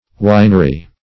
Winery \Win"er*y\, n. [Cf. F. vinerie.]